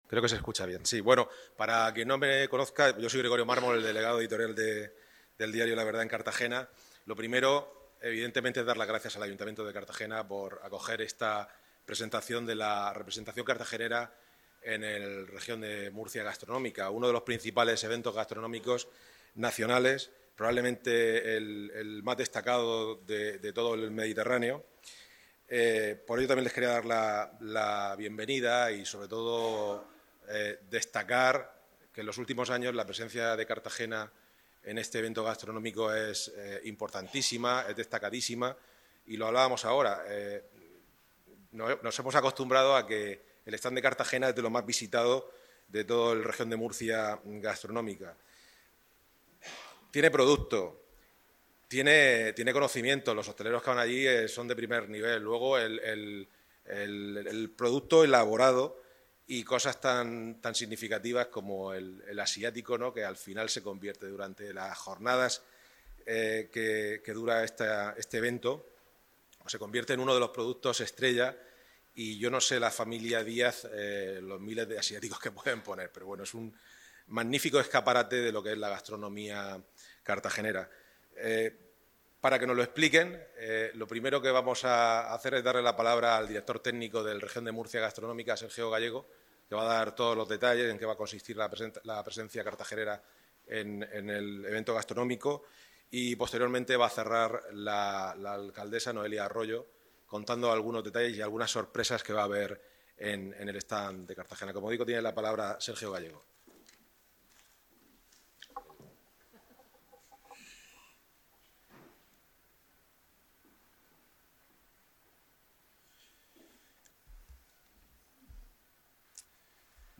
Declaraciones